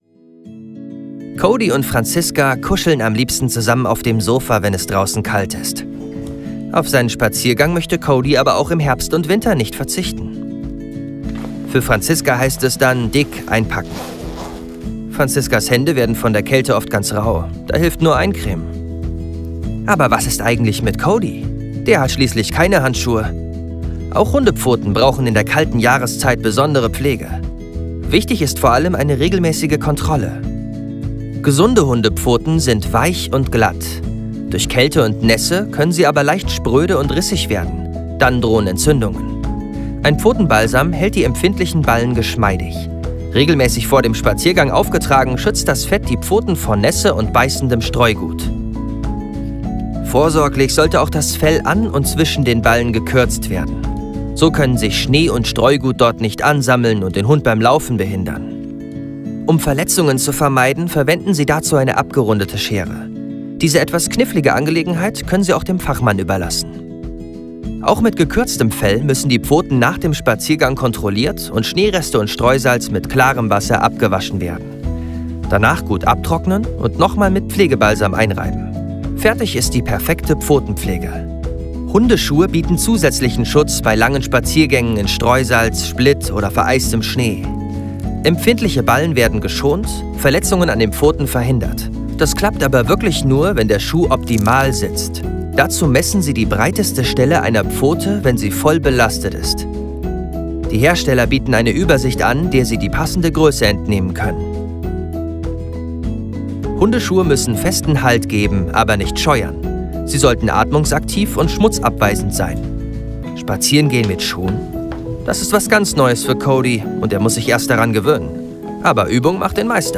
Synchronsprecher, Hörspieler, Off-Stimme, Stationvoice
Sprechprobe: Industrie (Muttersprache):
Young, versatile, dynamic and upbeat. Located in Germany with own ISDN studio.